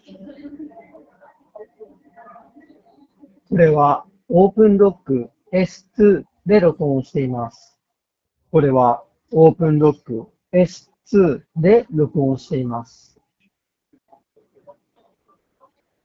通話時のノイズキャンセリング
スピーカーから雑踏音をそこそこ大きなボリュームで流しながらマイクで収録した音声がこちら。
完全に雑音を除去しているわけではないですが、これくらいなら通話相手が聞き取りにくいことはなさそうです。
マイクもクリアで良い感じなので、仕事でのちょっとした打ち合わせ程度であれば十分使えると思います。
openrock-s2-voice.m4a